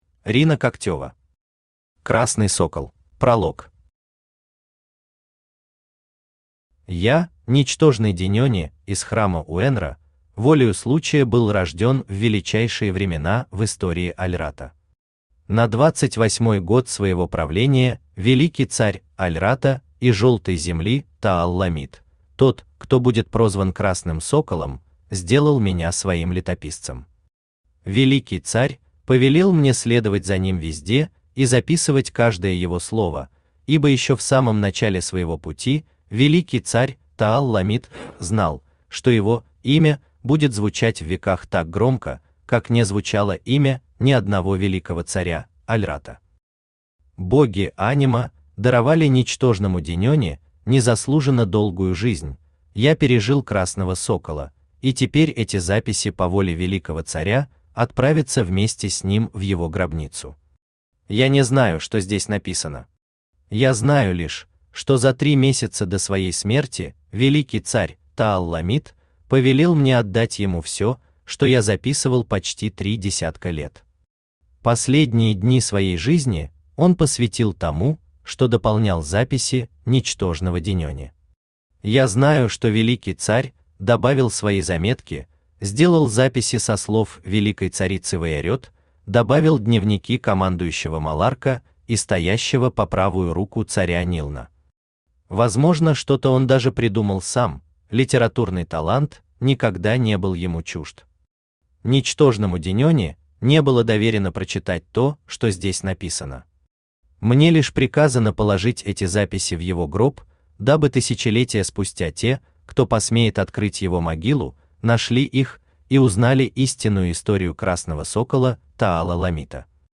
Aудиокнига Красный сокол Автор Рина Когтева Читает аудиокнигу Авточтец ЛитРес. Прослушать и бесплатно скачать фрагмент аудиокниги